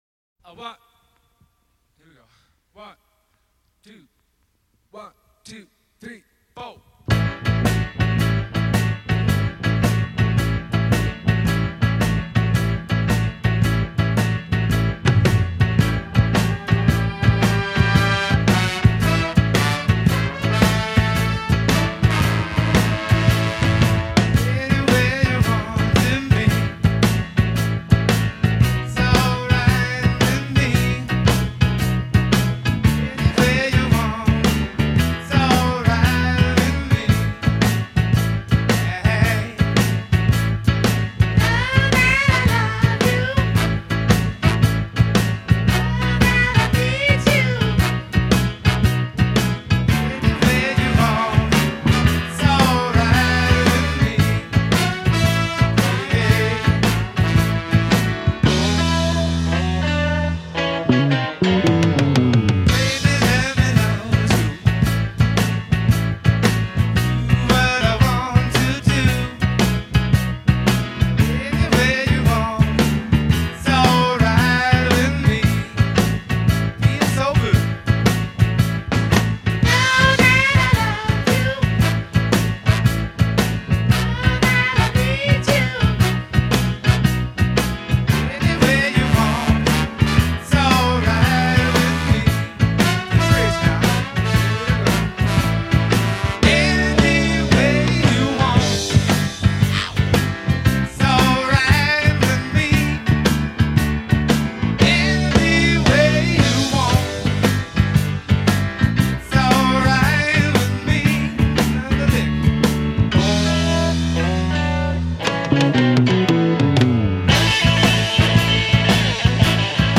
It’s a simple ditty
the horn charts
guitar solo, and an uplifting vibe.